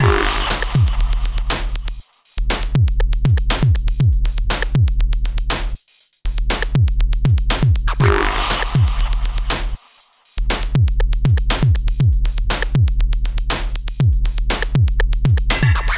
TSNRG2 Breakbeat 002.wav